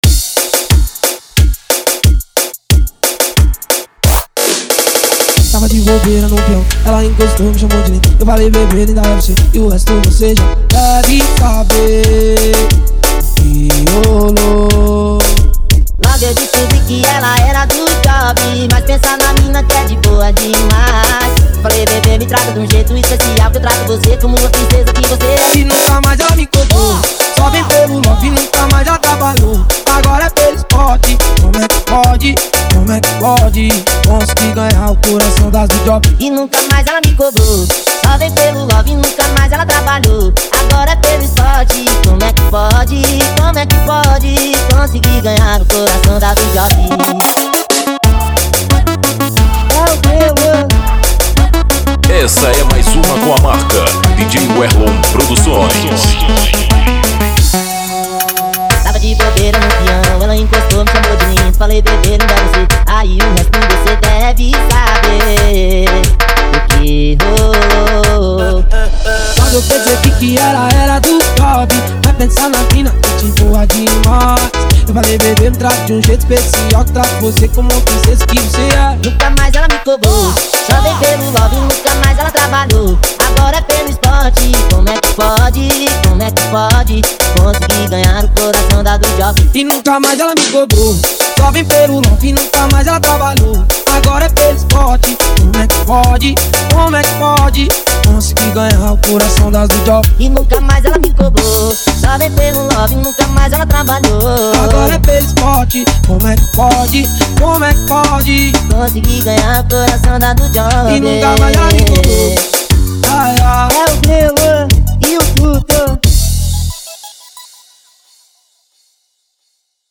OUÇA NO YOUTUBE Labels: Tecnofunk Facebook Twitter